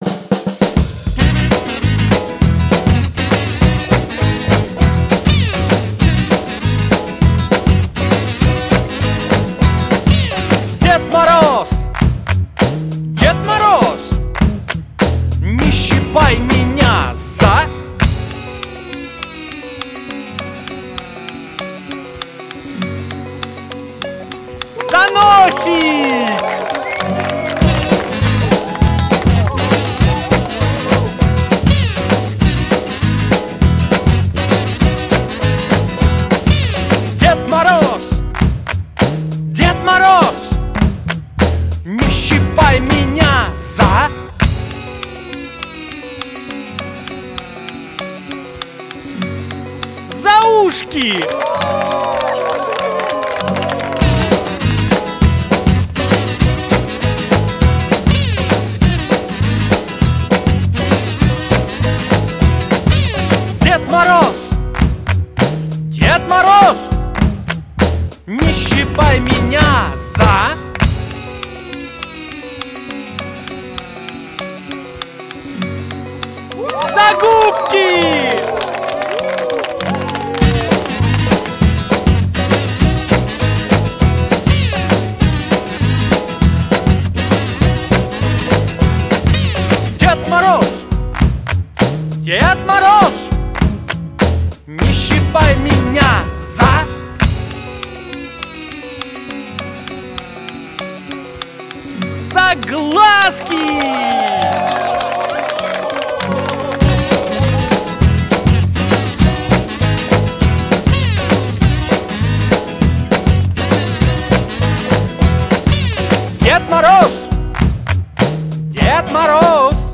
Детские песни В закладки 😡 Замечание!